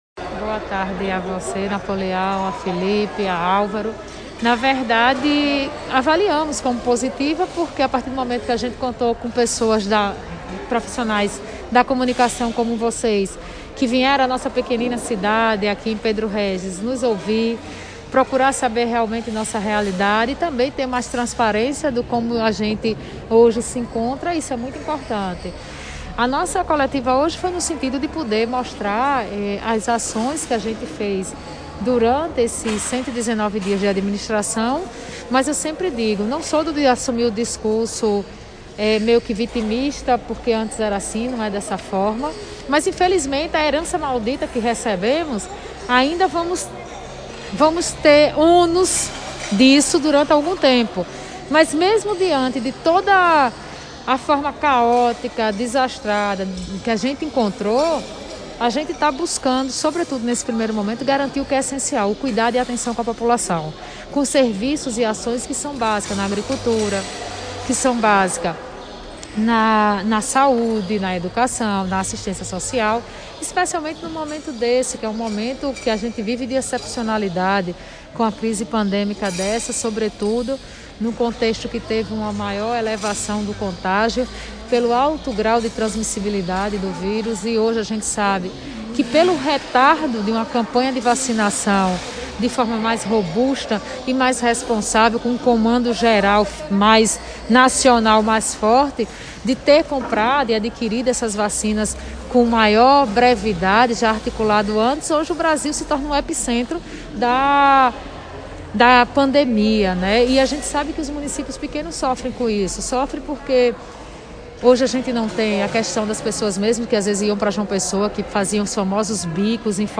A prefeita de Pedro Régis, Michele Ribeiro, participou na manhã da última quinta-feira (29) de uma entrevista coletiva com profissionais da Imprensa da região do Vale do Mamanguape e do Brejo paraibano, para realizar um balanço dos 100 primeiros dias do seu governo, e anunciou uma série de ações administrativas em comemoração aos 27 anos de Emancipação Política da cidade.
Ouça entrevista com a prefeita Michele: